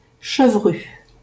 Chevru (French pronunciation: [ʃəvʁy]